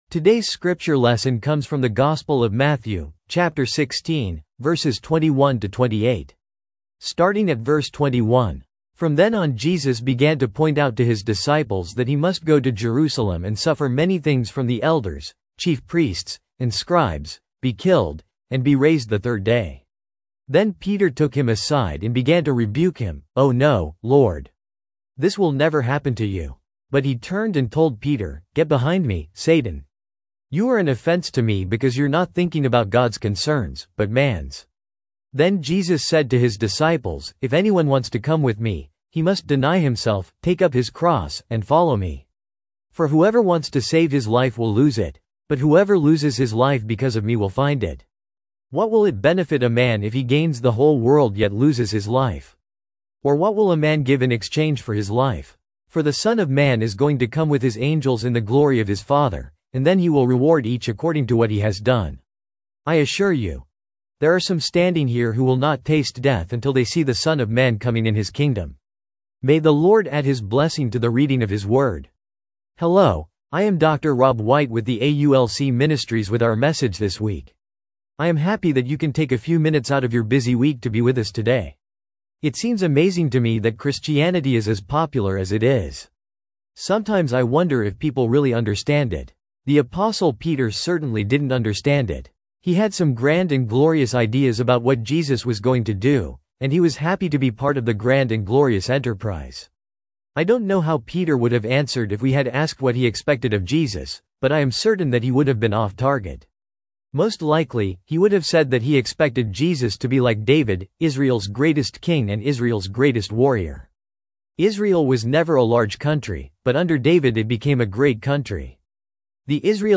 " Topical Series " The Right Stuff Award Sermon Notes